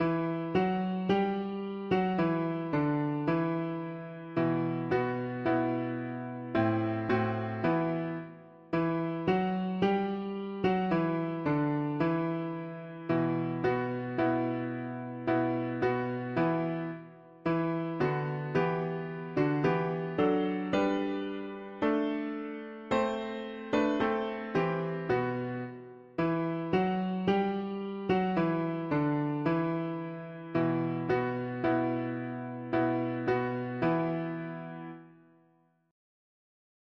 Irish folk song
Key: E minor Meter: 76.76 D